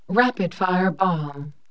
BOMB_RIF.WAV